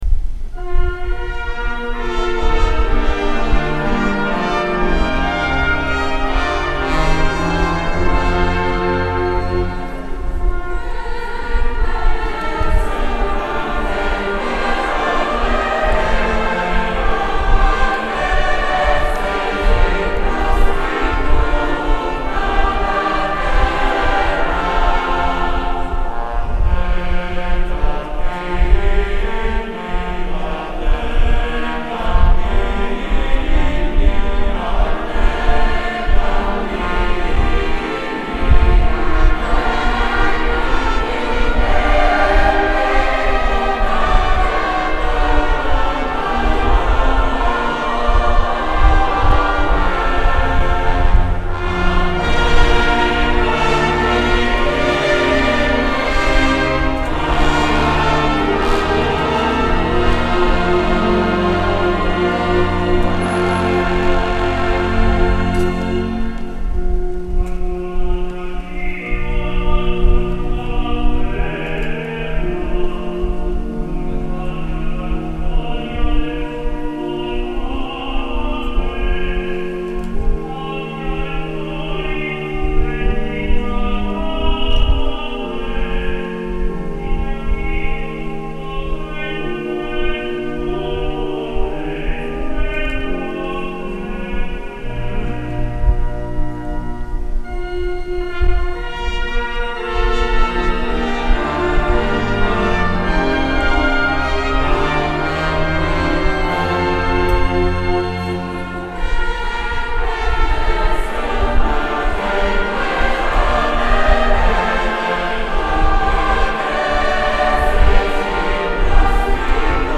Gallery >> Audio >> Audio2022 >> Ordinazione Arcivescovo Isacchi >> 13-Offertorio OrdinazArcivescovo 31Lug22